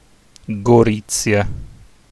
Gorizia (Italian pronunciation: [ɡoˈrittsja]